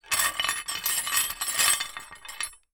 Metal_63.wav